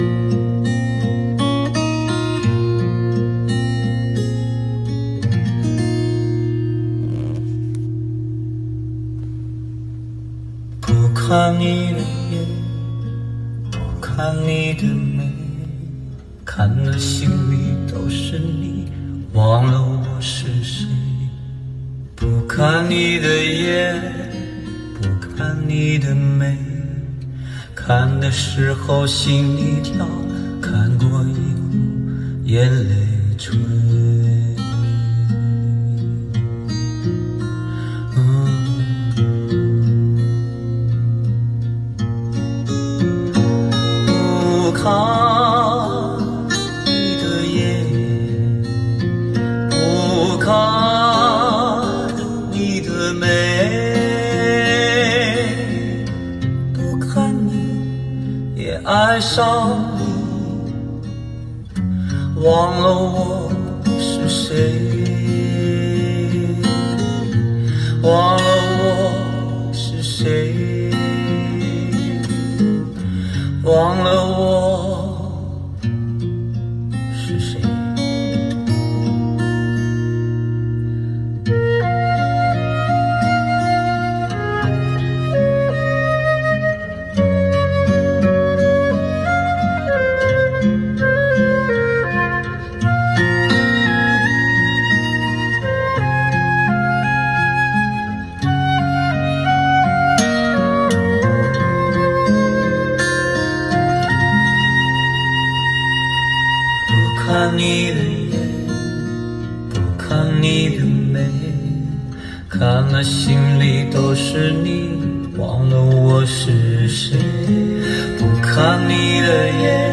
路虎揽胜音响效果试听
本次试听的是MERIDIAN™825W音响系统，在车舱内精心布局的19个扬声器(含1个低音炮)，825瓦的功率，带来优美清澈的高音和深沉浑厚的低音。
得益于以上方面的全面协同，使得这款顶级座驾的音响效果无论从高中低三个频段，还是人声的还原、乐器的精准定位方面，都表现均衡，其中高音部分和低音部分音响尤为深刻，特别得益于巨大的车内空间，音色纯正的吉他、沉重有力的低音鼓、以及真实再现的现场感感染力都得益完美体现。
1700W高级音响效果.mp3